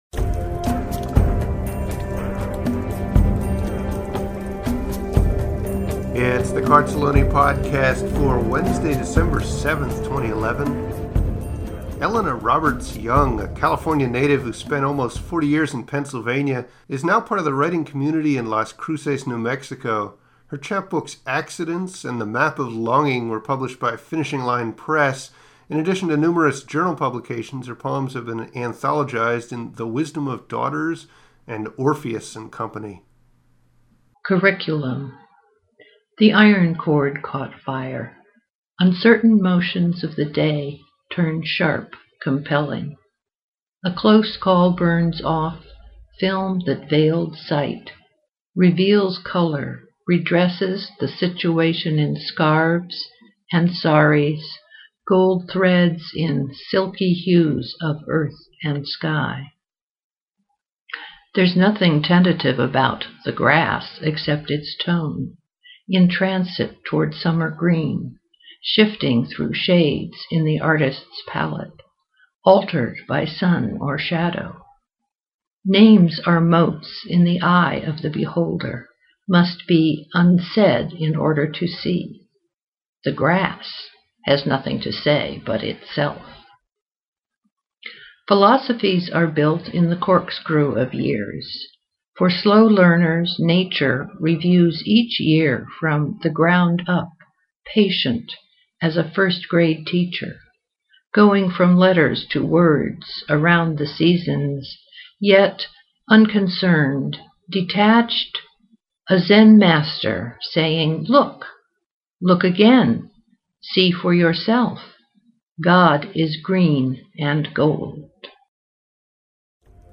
I loved the poet’s reading and the vivid imagery of her poem!
The vivid imagery of the poets thoughts are transcribed and spoken with earthy emotion.